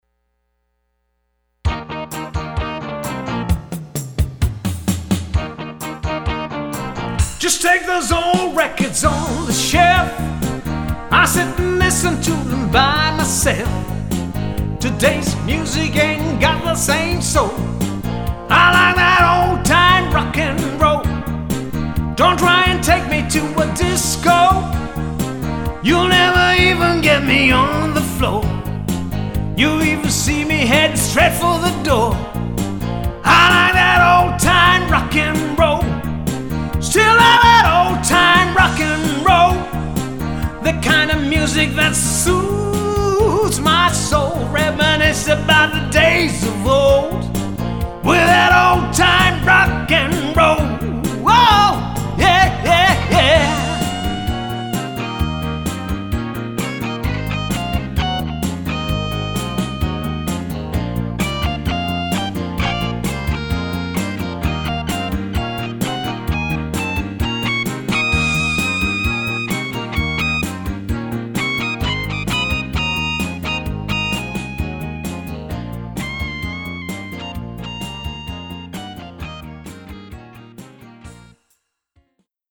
50’s & 60’s Golden Pop Oldies